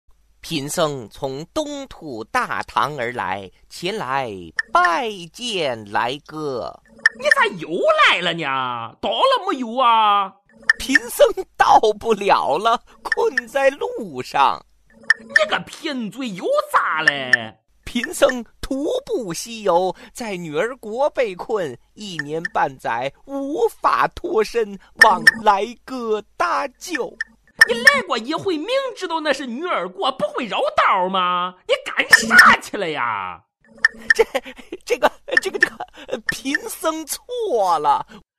搞笑铃声